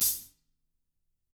Index of /90_sSampleCDs/ILIO - Double Platinum Drums 2/Partition D/THIN A HATD